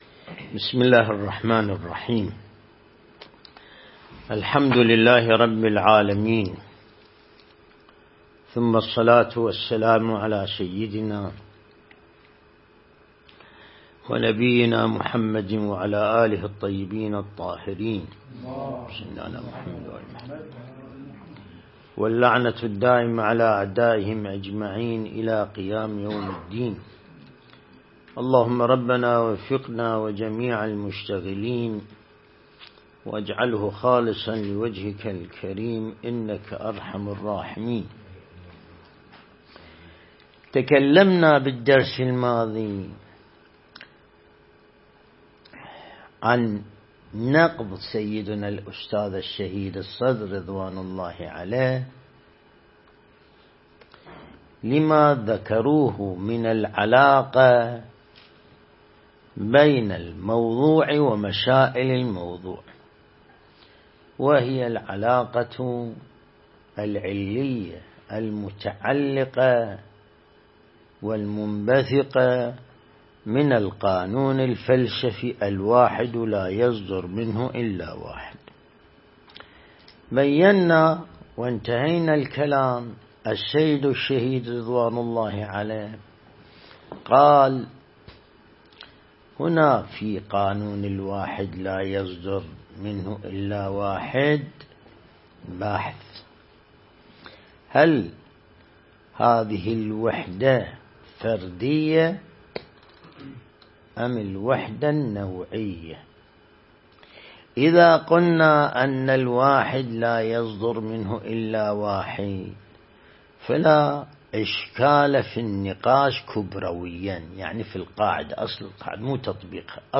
درس (35)
النجف الأشرف